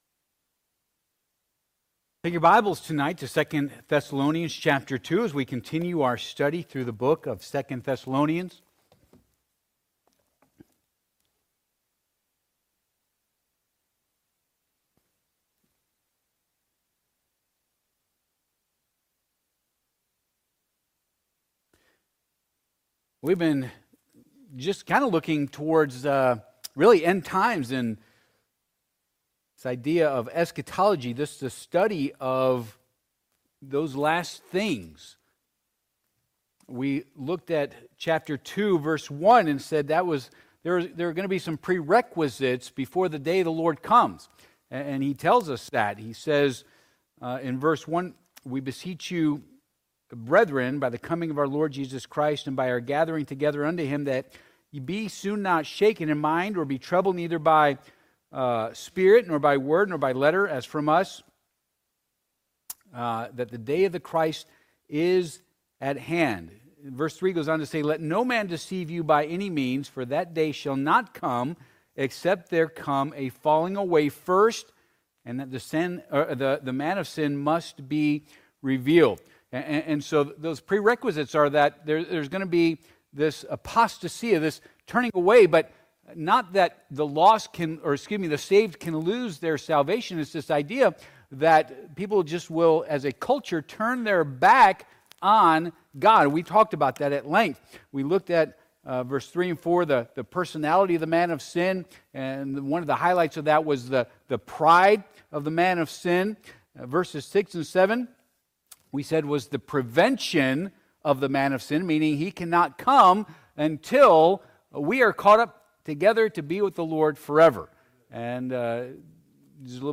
2 Thess. 2:8-10 Service Type: Midweek Service « What Are You Looking At?